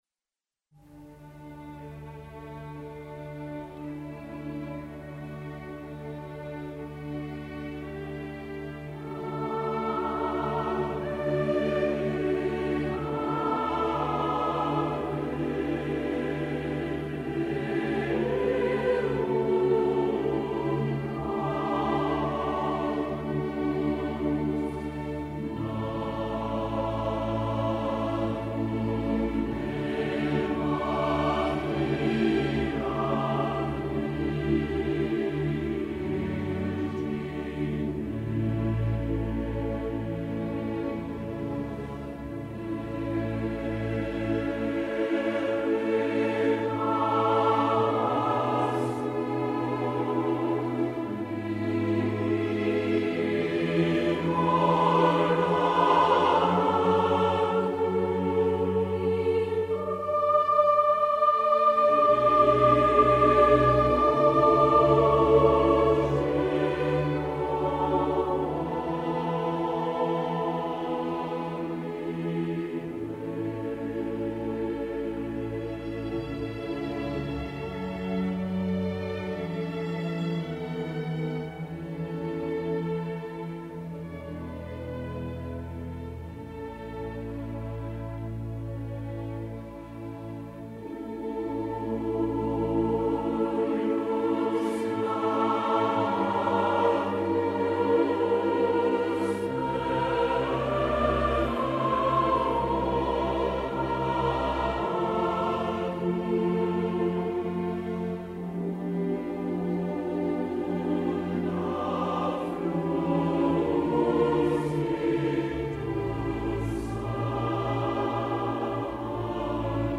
谢谢楼主....轻快极适合小朋友